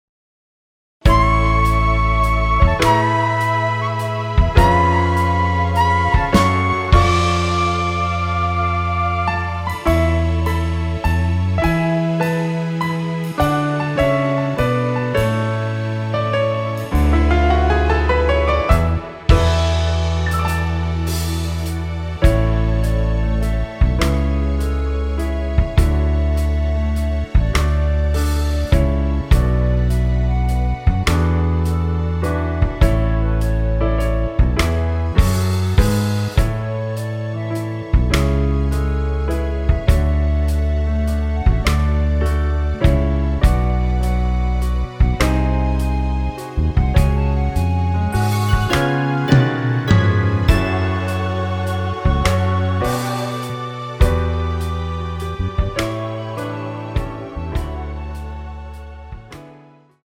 원키에서(+2)올린 멜로디 포함된 MR입니다.
멜로디 MR이라고 합니다.
앞부분30초, 뒷부분30초씩 편집해서 올려 드리고 있습니다.
중간에 음이 끈어지고 다시 나오는 이유는